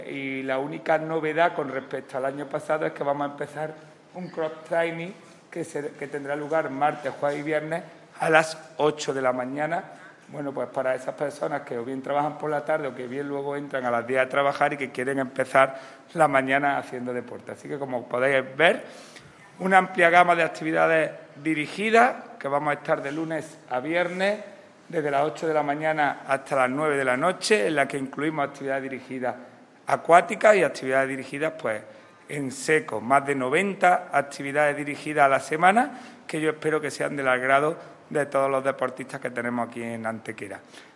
El teniente de alcalde delegado de Deportes del Ayuntamiento de Antequera, Juan Rosas, informa del inicio de las actividades colectivas dirigidas de la nueva temporada 2021/2022 tanto en lo que respecta a las salas multidisciplinares del Pabellón Polideportivbo Fernando Argüelles como en la Piscina Cubierta Municipal.
Cortes de voz